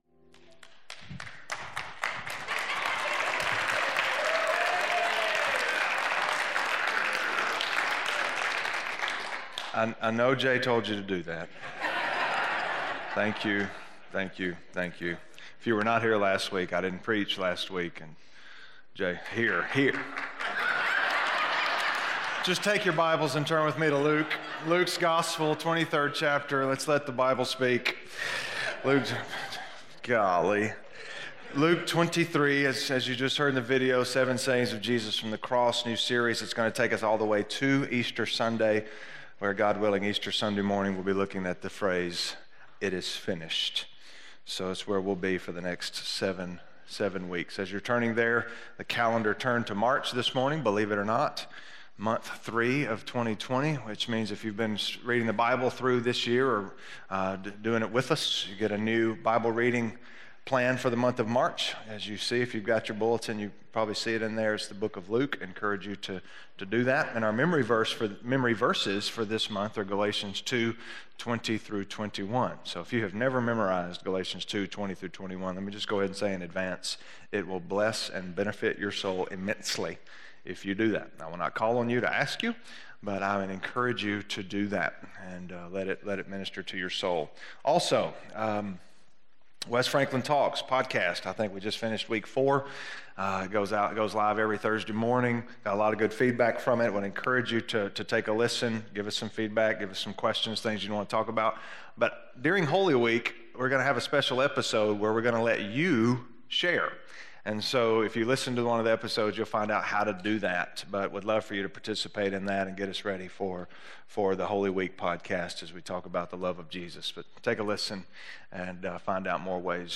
Father, Forgive Them - Sermon - West Franklin